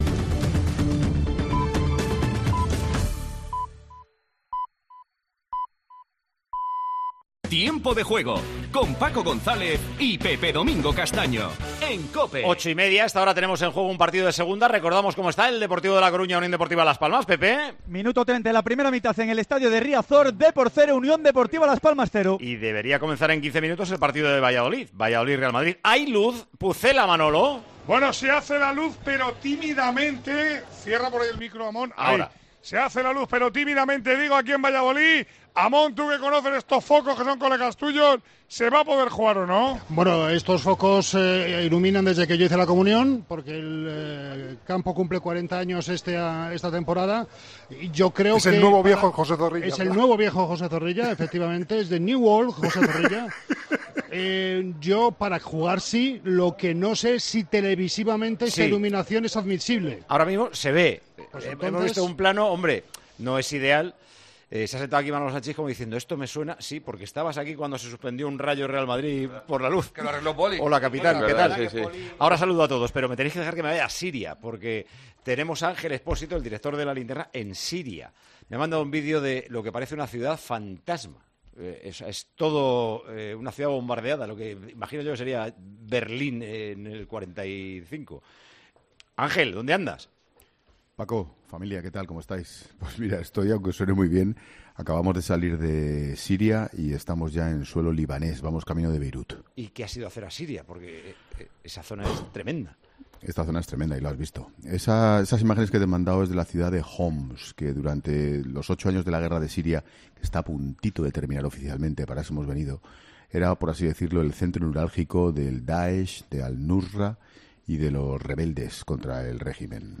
“La guerra está a punto de acabar, pero todo queda por reconstruirse”, explica en una conexión con Paco González en 'Tiempo de Juego'.